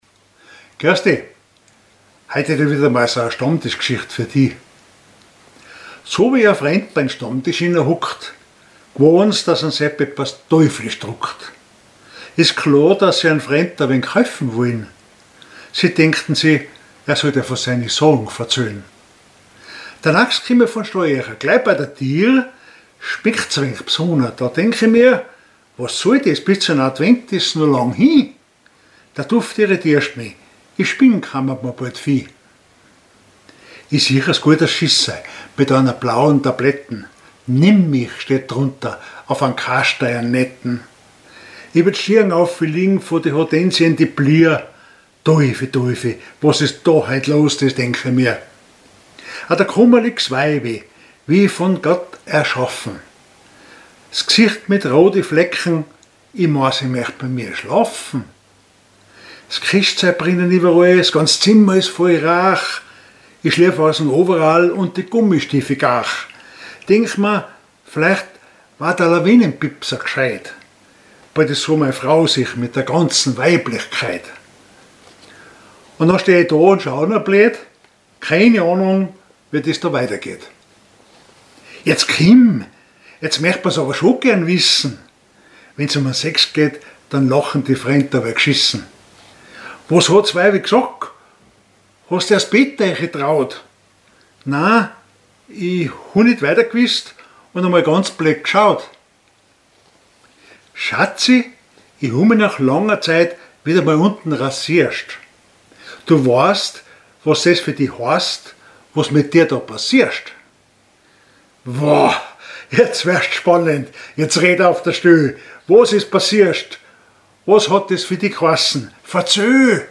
Mundart
Gedicht Monat November 2025
Type: witzig